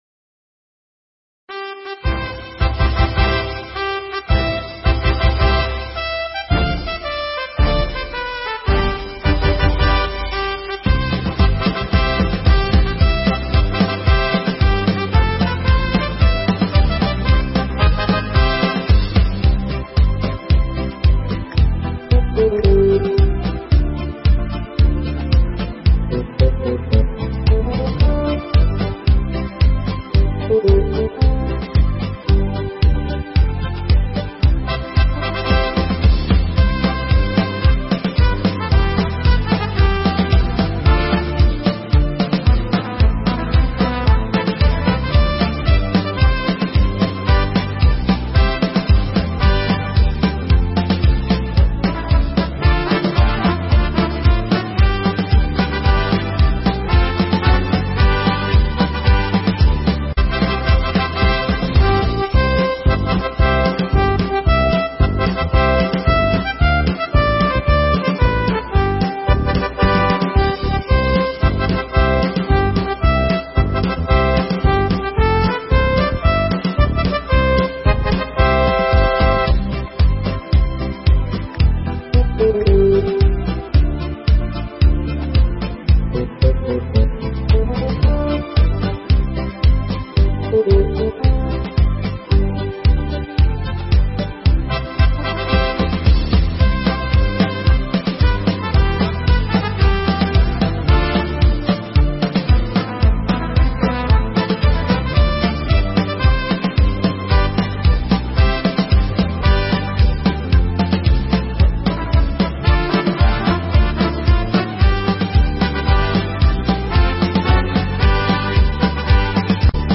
File nhạc không lời